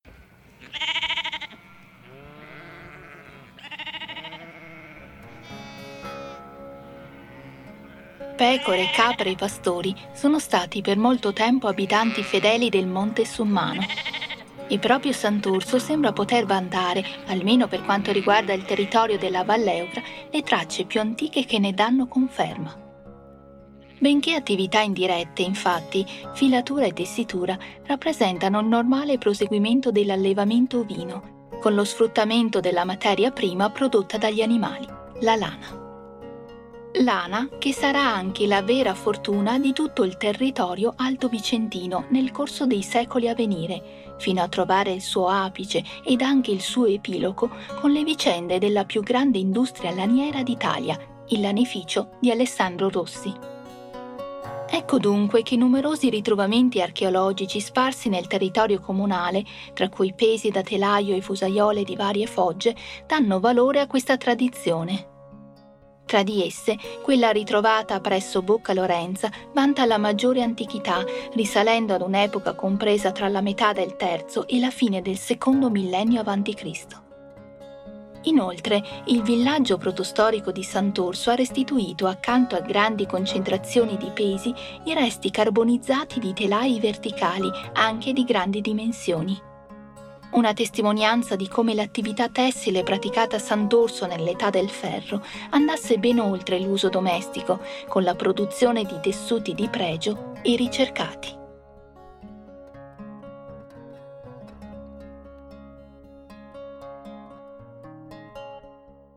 AUDIOGUIDA_Girolimini._09._Lana_e_pastori.mp3